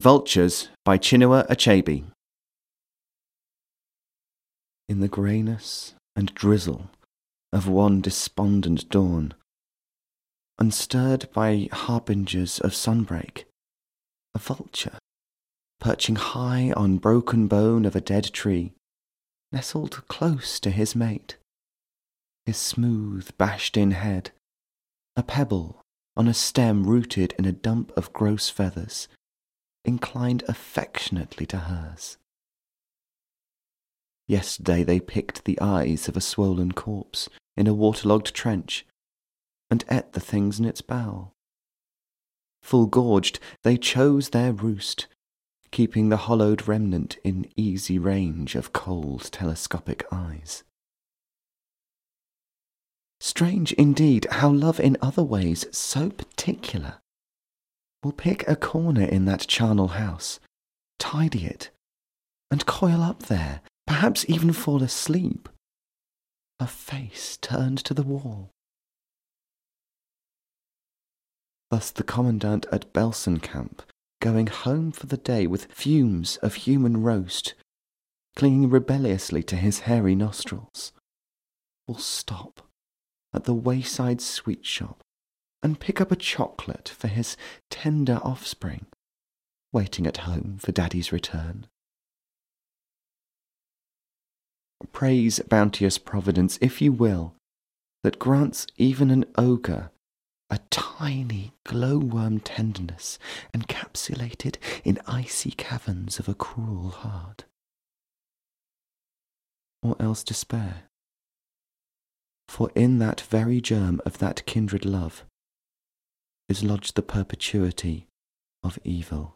Male
English (British)
Adult (30-50)
Natural Speak